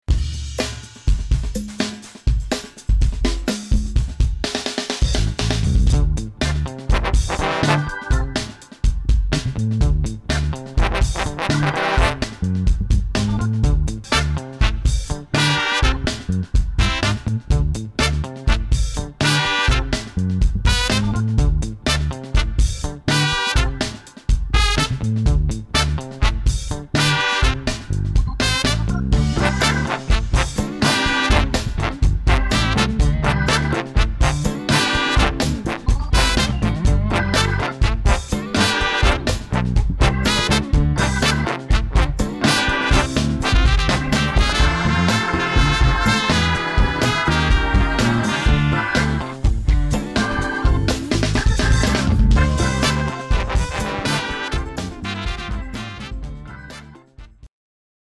Big Band Besetzungen
Erweiterung der Soulfunk-Band